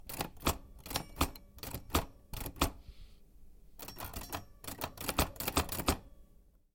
打字机
标签： 打字机 点击 新闻 机械 钥匙 唠叨 按键
声道立体声